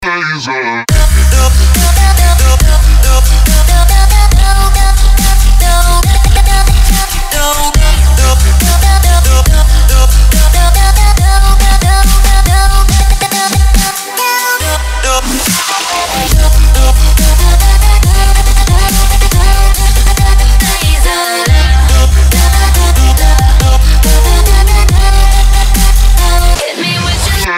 красивые
Хип-хоп
Trap